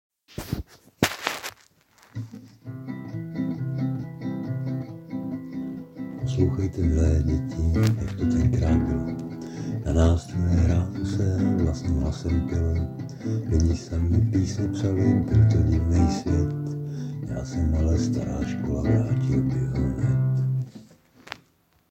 Anotace: Písnička bude, zatím jen nástřel první sloky:-)